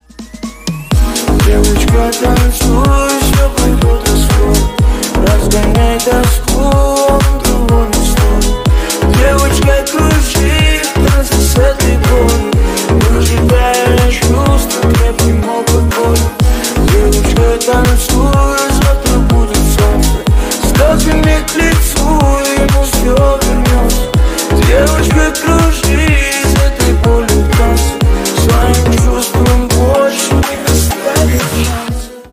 Ремикс
грустные